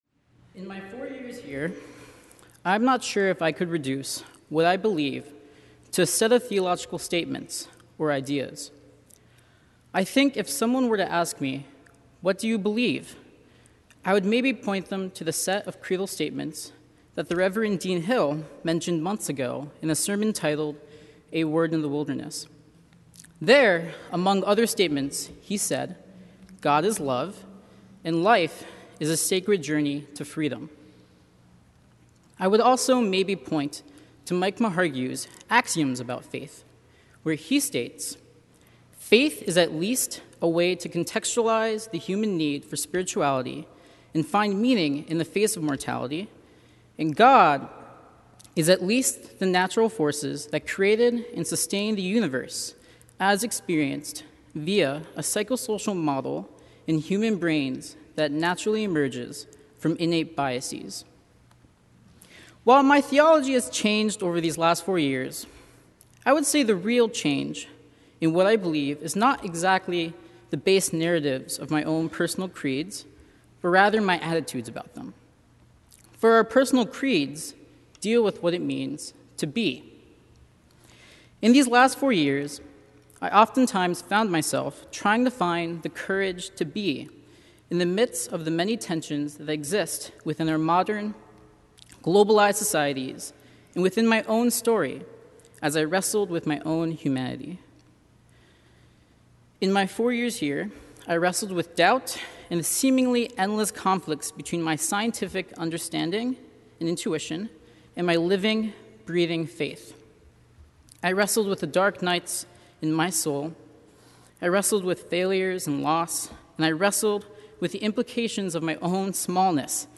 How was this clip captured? Graduating students share personal stories during Marsh Chapel’s “This I Believe” Sunday